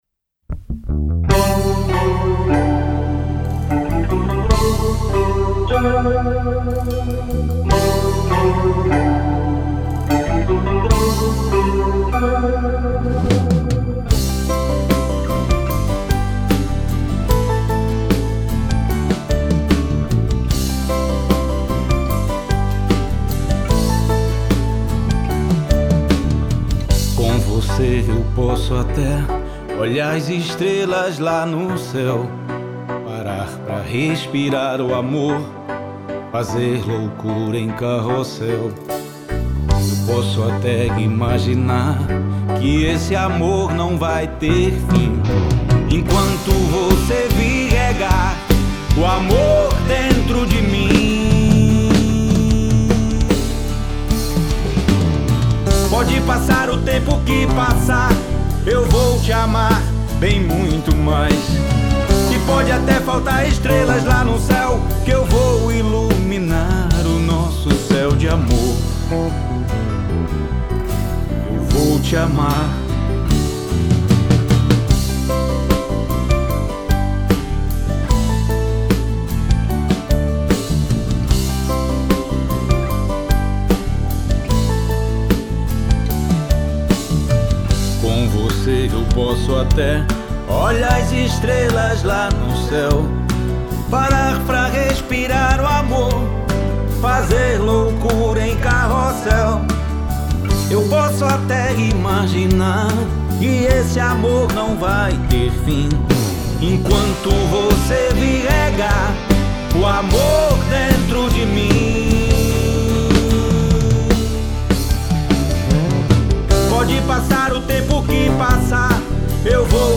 mergulha no universo dos ritmos da música popular brasileira